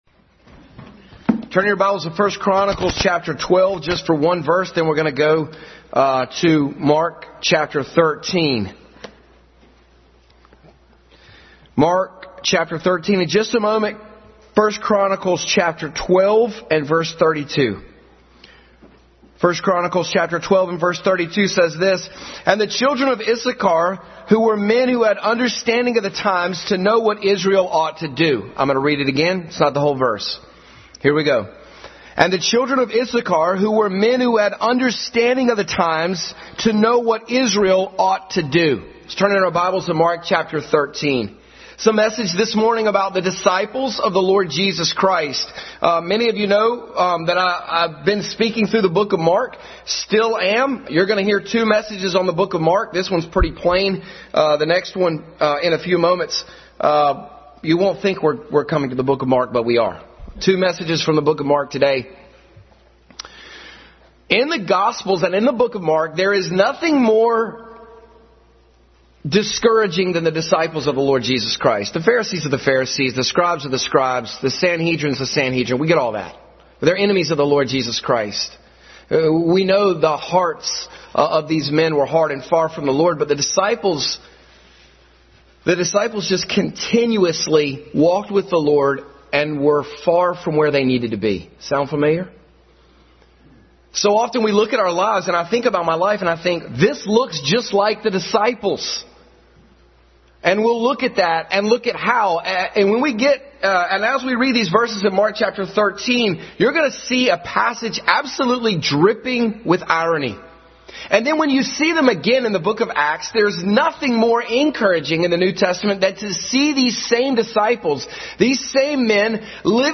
Adult Sunday School.